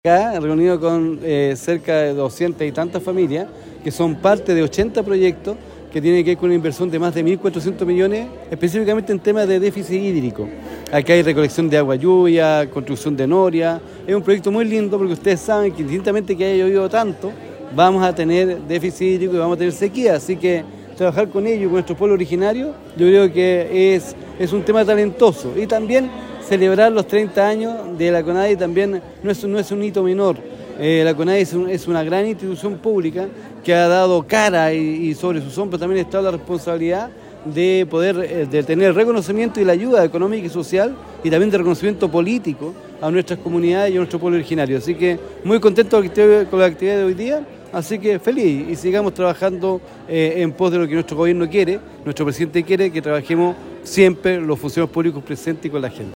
Son más de 200 familias que son parte de los 80 proyectos que tienen una inversión de más de 1.400 millones, específicamente en temas de déficit hídrico, donde se trabaja con programas recolección de agua lluvia, construcción de noria, entre otros como lo explicó el Seremi de Desarrollo Social y Familia, Enzo Jaramillo.